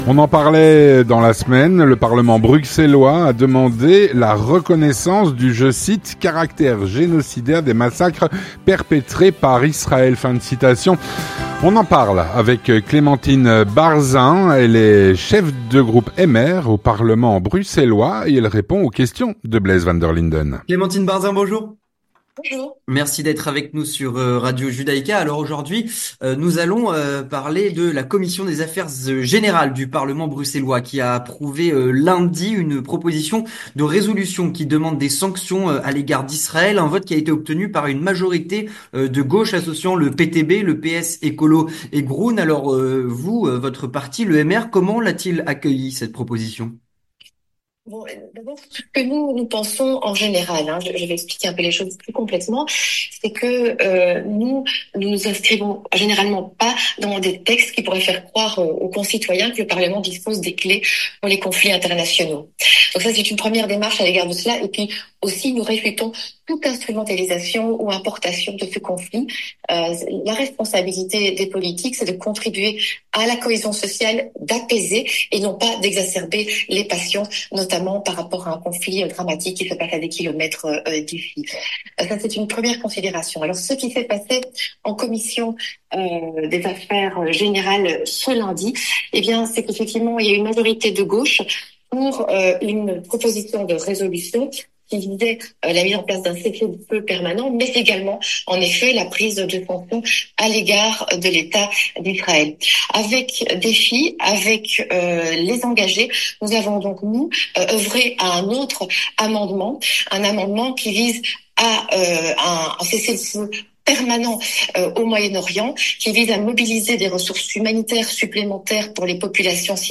On en parle avec Clémentine Barzin, cheffe de groupe MR au parlement bruxellois.